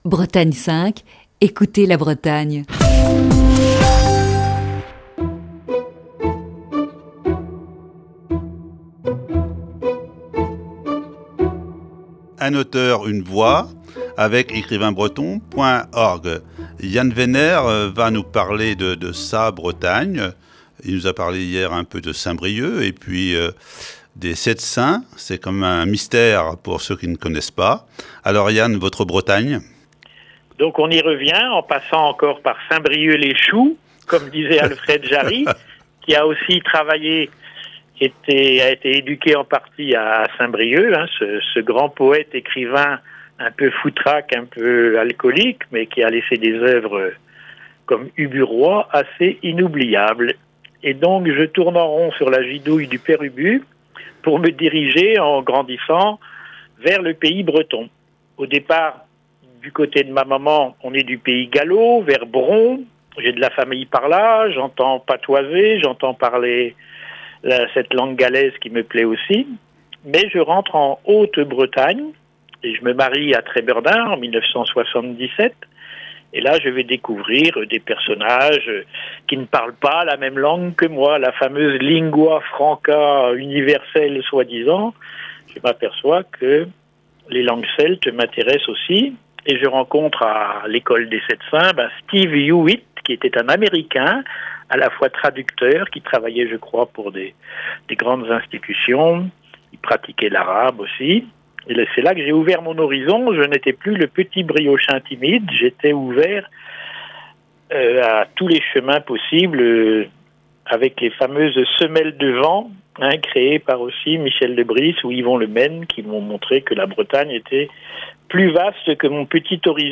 Ce matin, deuxième partie de cette série d'entretiens.